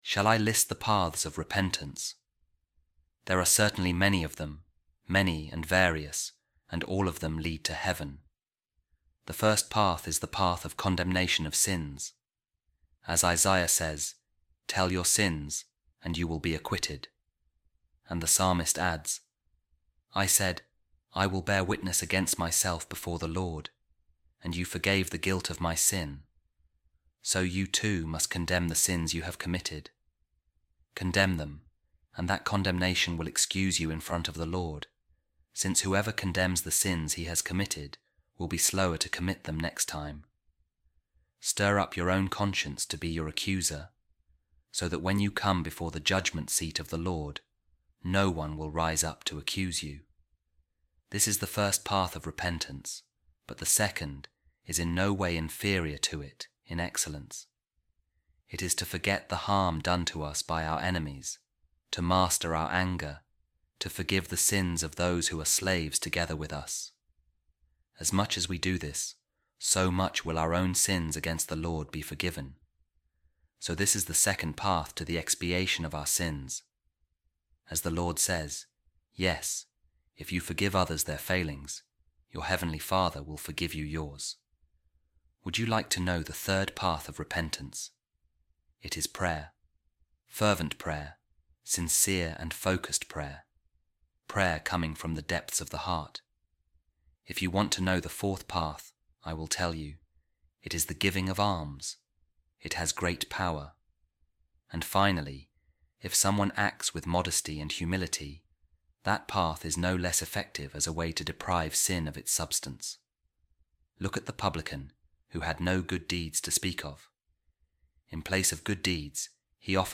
Office Of Readings | Week 21, Tuesday, Ordinary Time | A Reading From The Homilies Of Saint John Chrysostom | Temptations Of The Devil | Paths Of Repentance